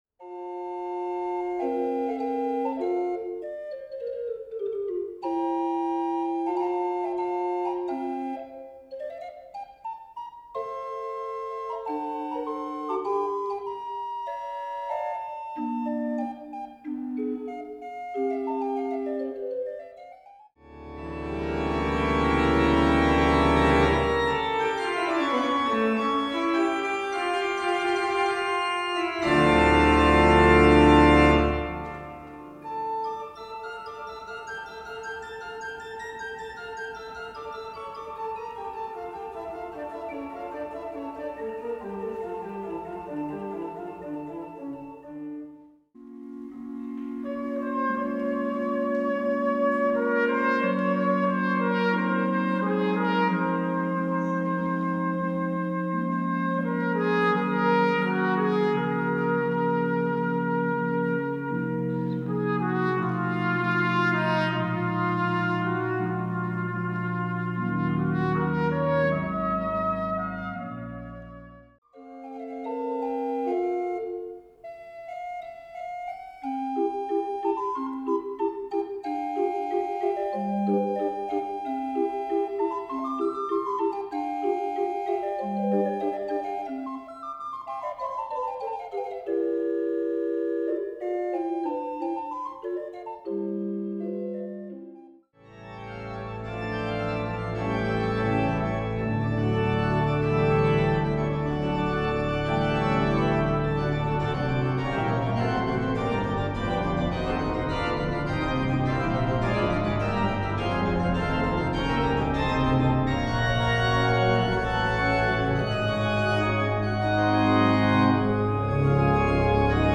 Orgel: Ev.
Orgel-Querschnitt.mp3